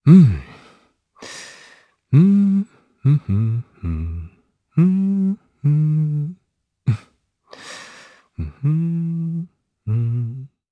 Siegfried-Vox_Hum_jp.wav